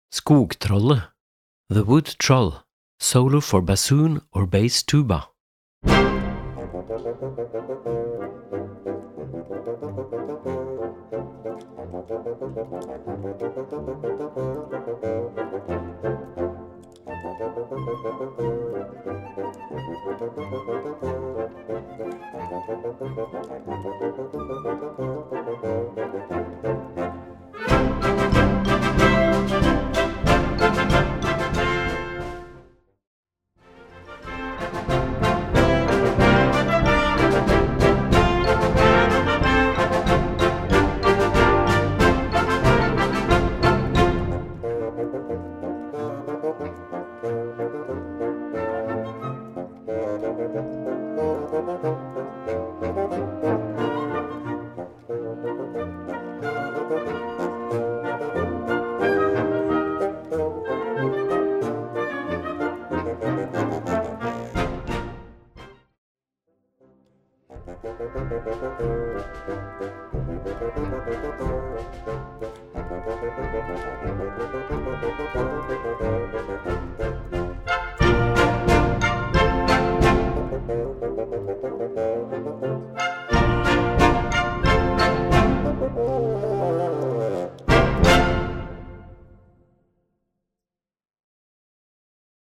Gattung: Solo für Fagott oder Tuba und Blasorchester
Besetzung: Blasorchester